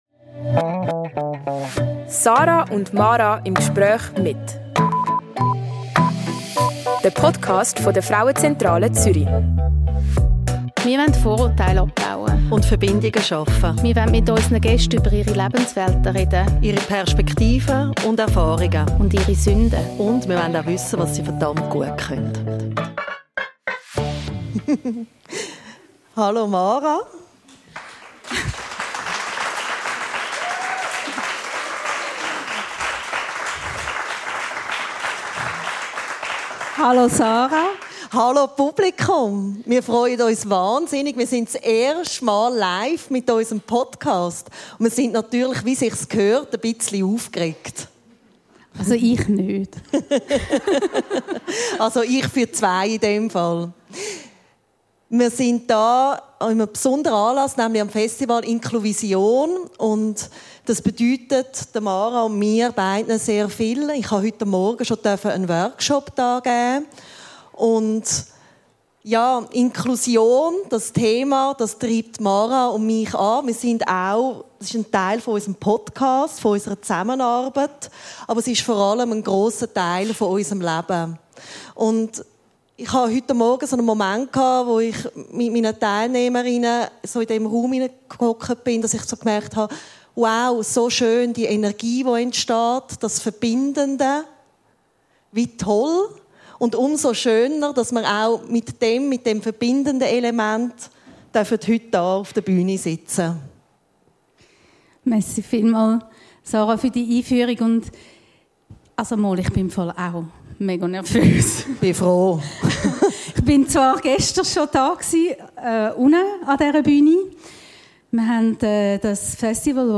(Live-Podcast)